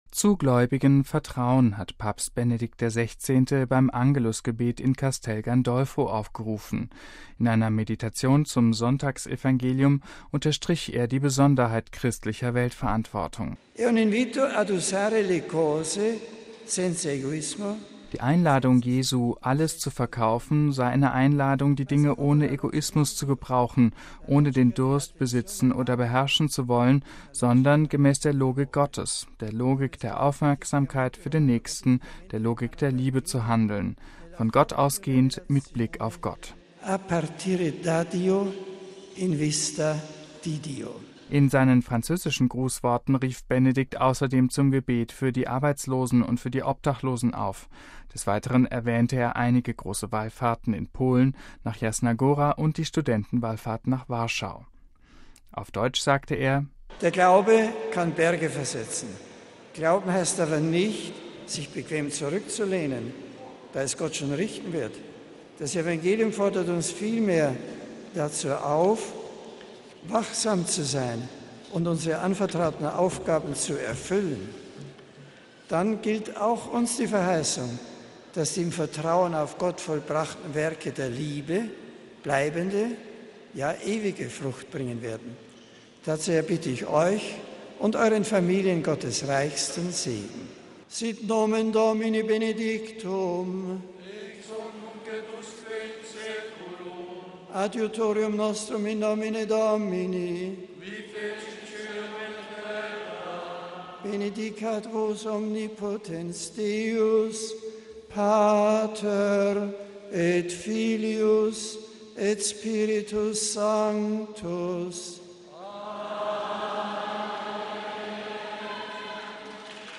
MP3 Zu gläubigem Vertrauen hat Papst Benedikt XVI. beim Angelusgebet in Castelgandolfo aufgerufen.